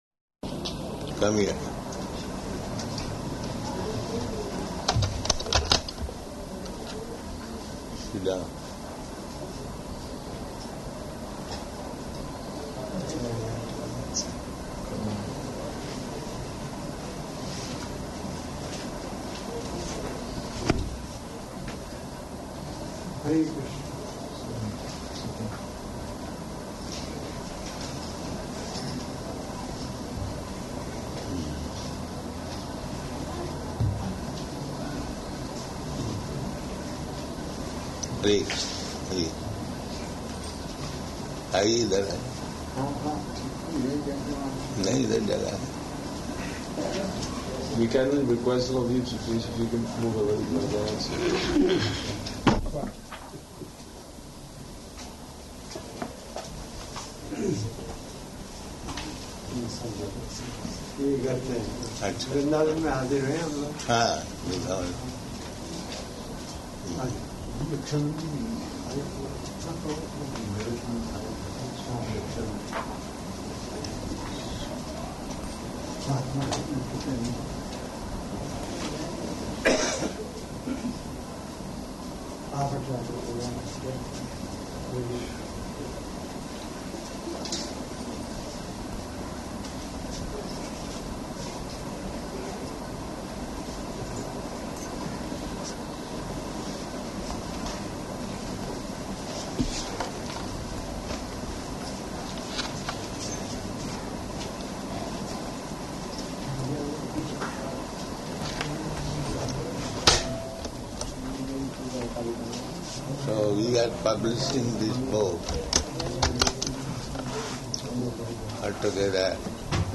Evening Darśana --:-- --:-- Type: Conversation Dated: May 12th 1977 Location: Rishikesh Audio file: 770512ED.HRI.mp3 Prabhupāda: Come here.